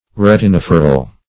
Retinophoral \Ret`i*noph"o*ral\